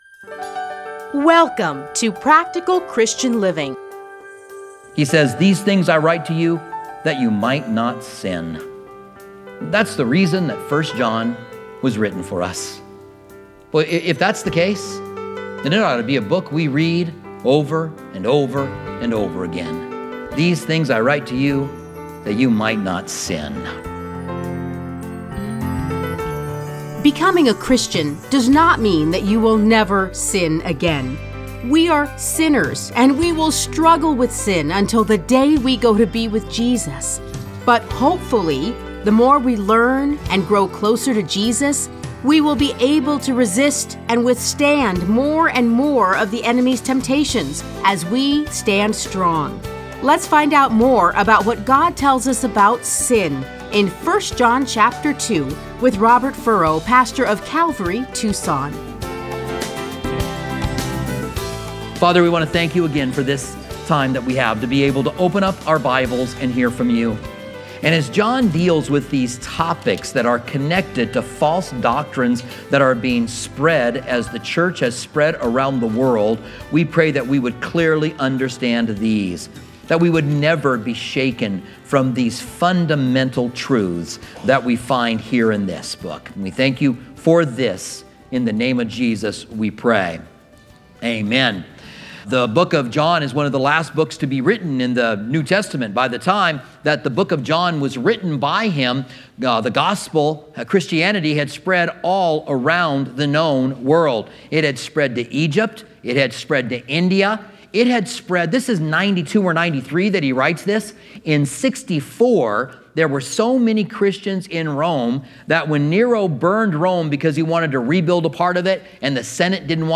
Listen to a teaching from 1 John 2:1-11.